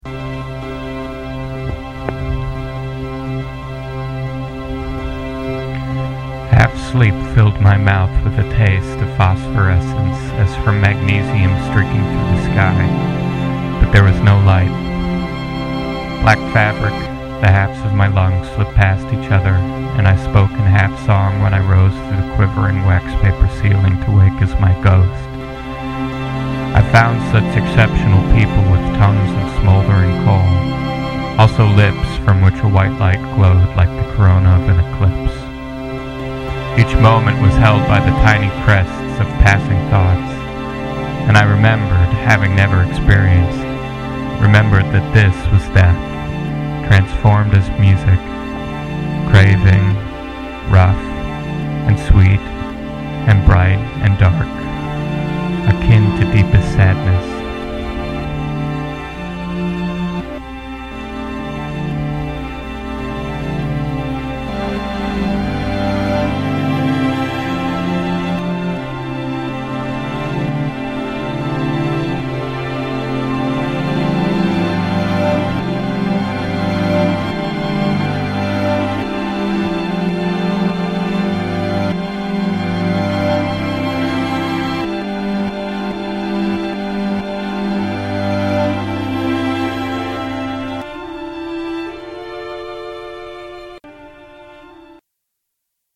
this is another thing that i worked on in 2007. the "telephone effect" on the vocals was not intentional. i think i recorded them with the tiny mic on my computer. the words are based on "an experience" by hugo von hofmannsthal, translated by mary kinzie.
i imagined that this would come out much better than it actually did. at the time i was very disappointed. i still like the string samples, but the sound of me talking over them is pretty lame.